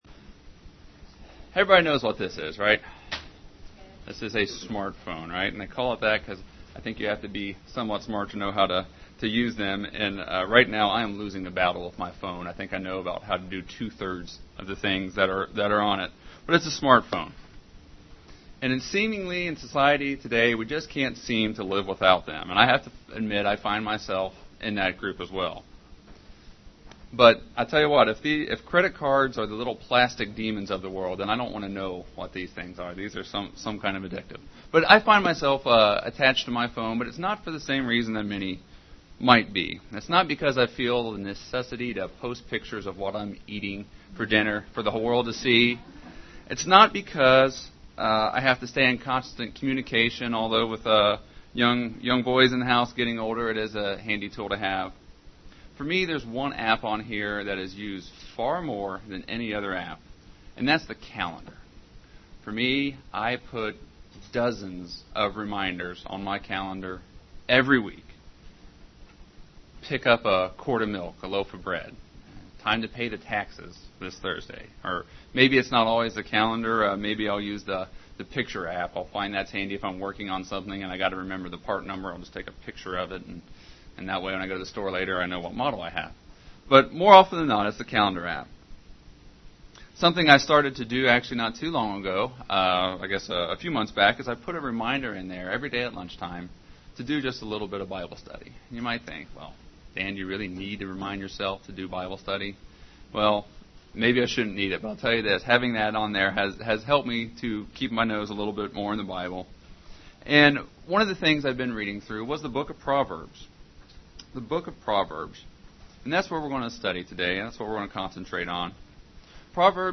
Print The book of Proverbs - A Book of Reminders UCG Sermon Studying the bible?